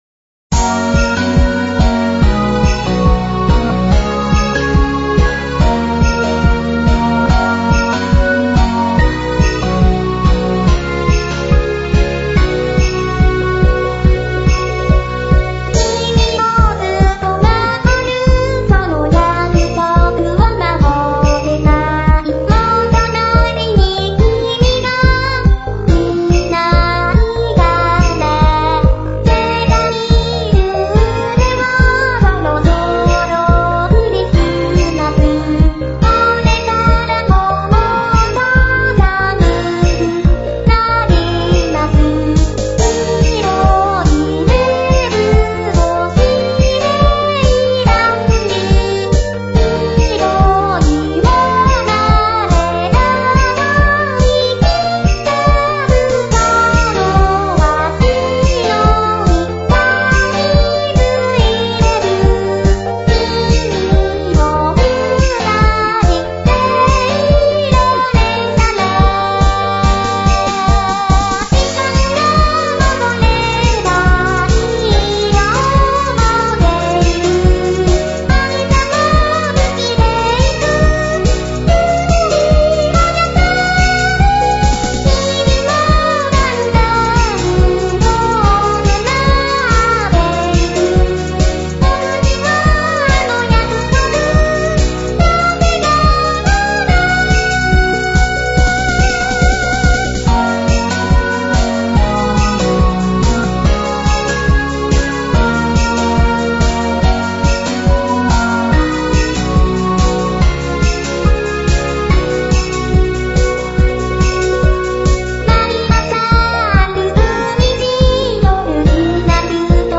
ＤＡＷ環境になり、ハードからソフト音源中心の環境になりました。
ボーカロイドを使った曲を中心に・・
ポップスすぎて、ダサくて歌ってられねーよという事になりました・・。